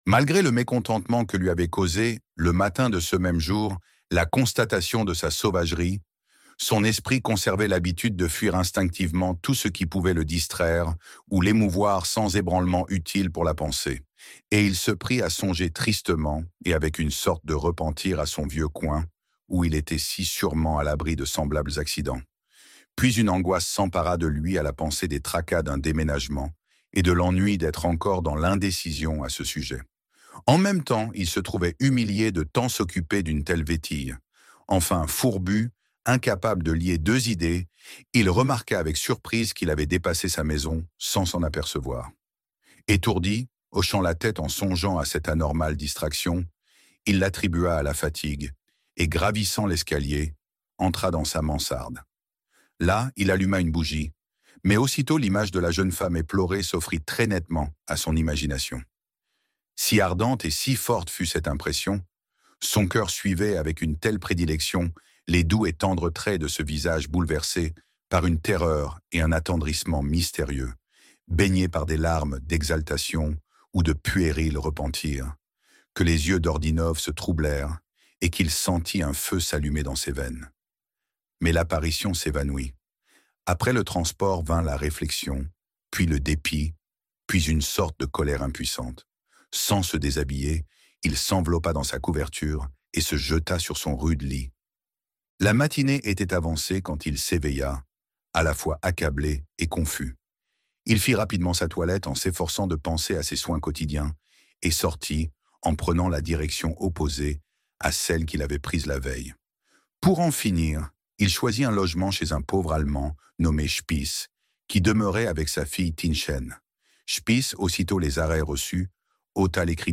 L'Esprit souterrain - Livre Audio